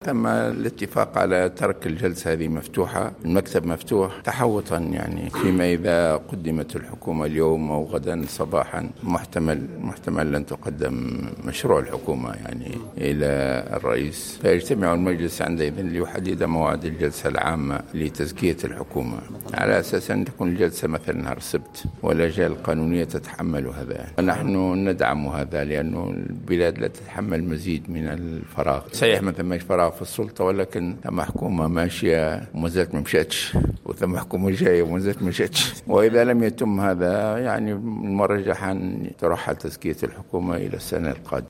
رجّح رئيس مجلس نواب الشعب راشد الغنوشي، في تصريح اليوم الخميس لموفد الجوهرة أف...